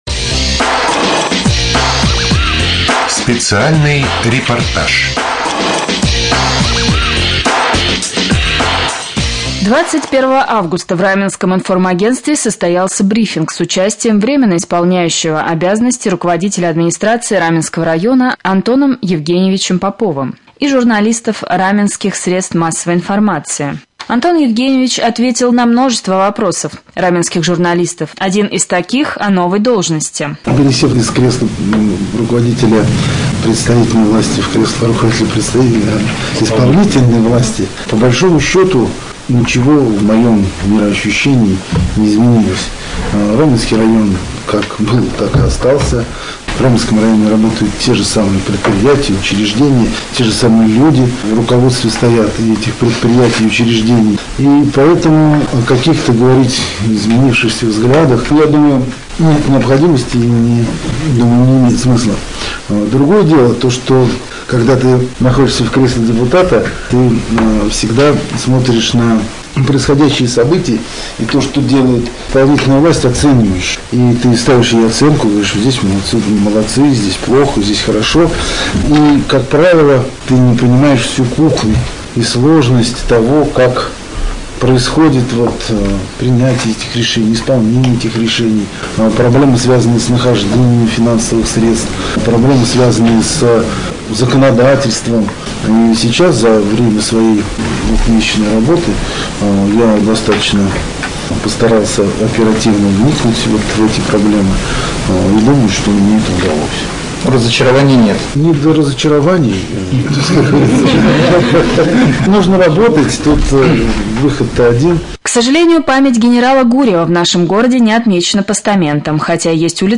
1. Рубрика «Специальный репортаж». 21 августа в Раменском информагентстве состоялся брифинг с участием врио руководителя администрации Раменского района А.Е.Попова и журналистов Раменских СМИ.